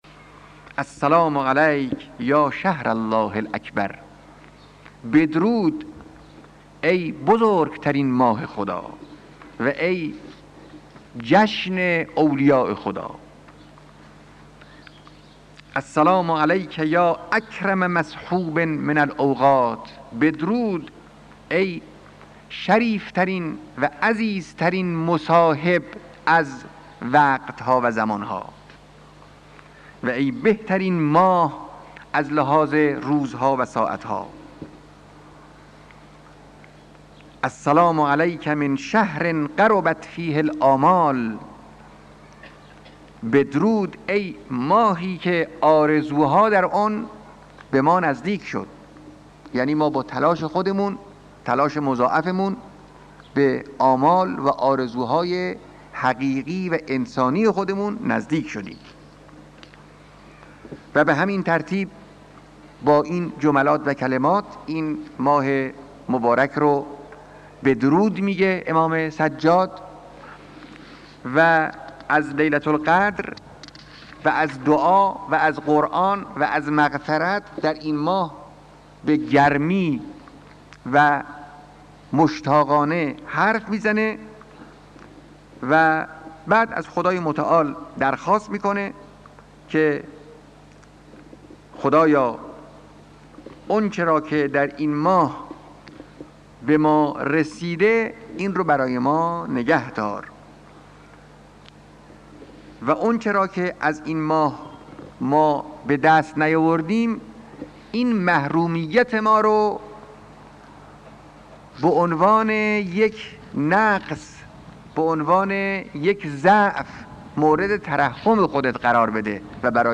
سخنرانی در روز ۲۹ رمضان در نهاد ریاست جمهوری ۱۳۶۶/۳/۷